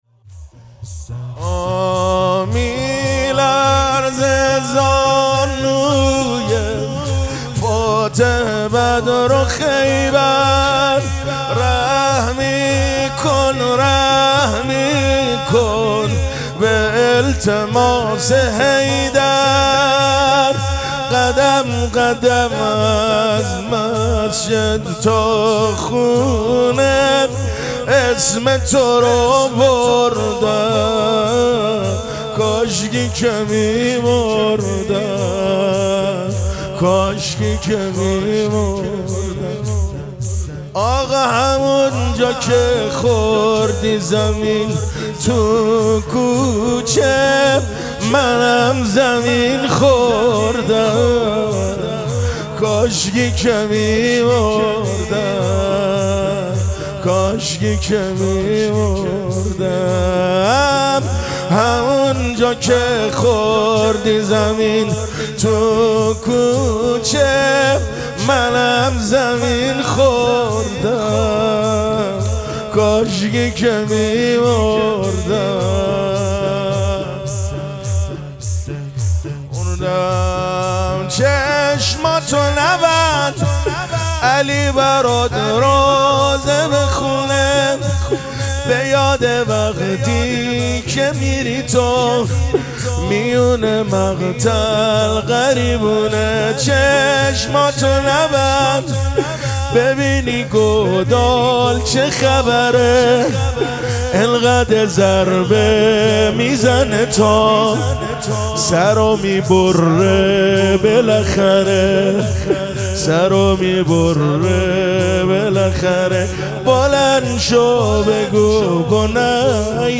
مراسم مناجات شب یازدهم ماه مبارک رمضان
مداح